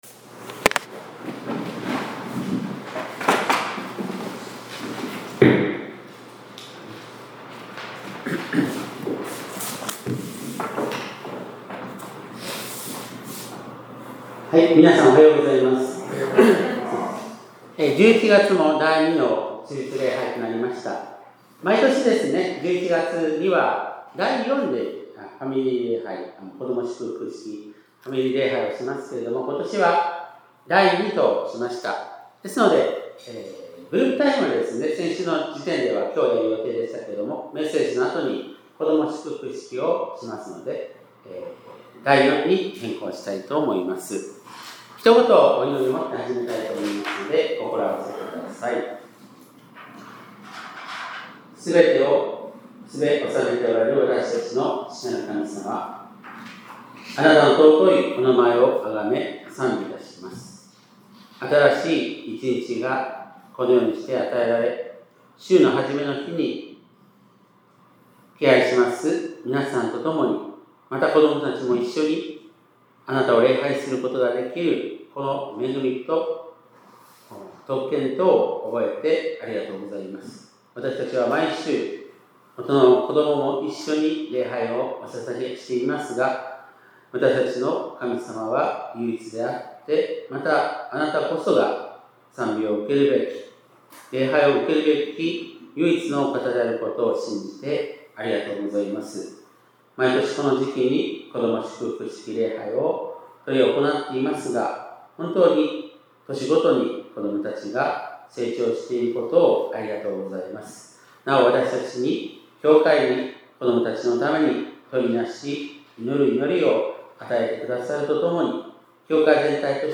2025年11月9日（日）子ども祝福式ファミリー礼拝メッセージ
＊本日は、子どもたちを祝福する記念のファミリー礼拝でした。